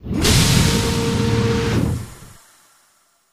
Airlock Door Open Lower Pitch